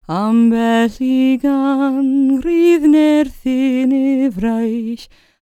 L CELTIC A13.wav